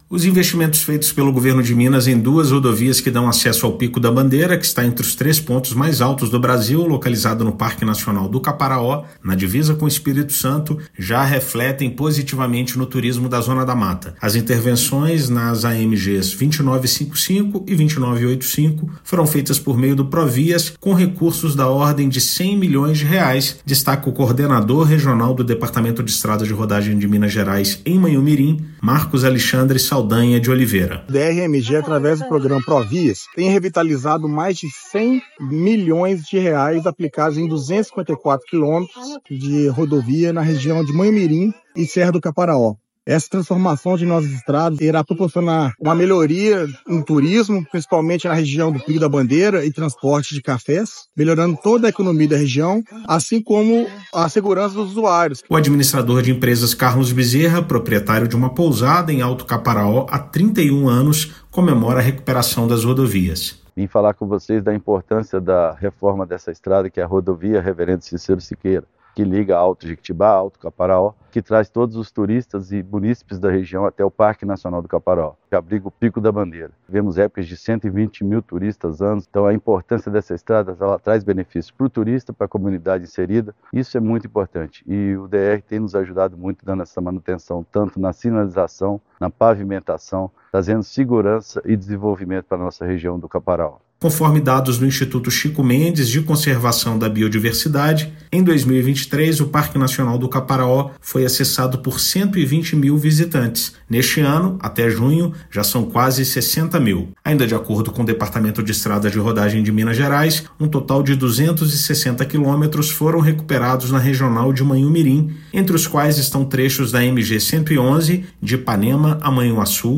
Investimentos favorecem o turismo da região, que abriga o terceiro ponto mais alto do Brasil, no Parque Nacional do Caparaó. Ouça matéria de rádio.